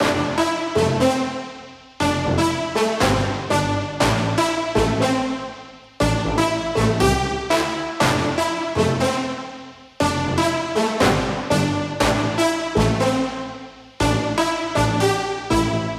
Index of /99Sounds Music Loops/Instrument Loops/Brasses